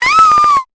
Cri de Nounourson dans Pokémon Épée et Bouclier.